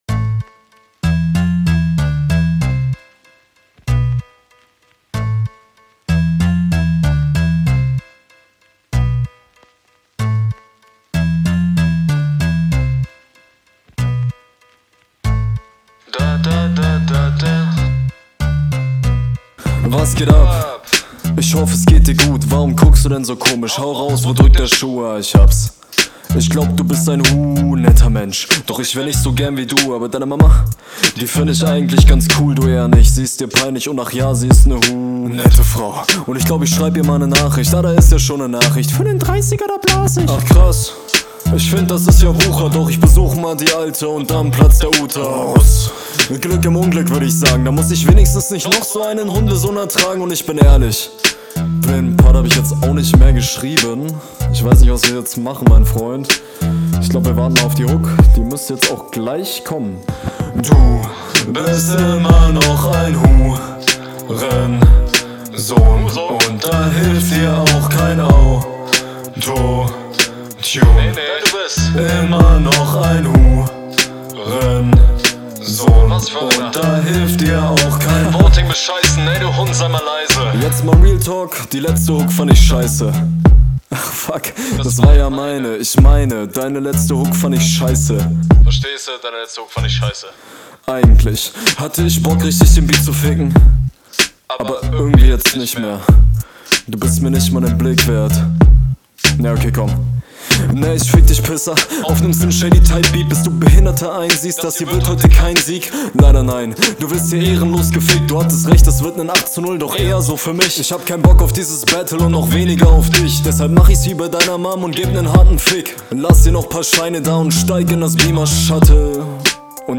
warum kommentiert der mann gefühlt seine eigene runde hahahahah und kontert noch die erste runde …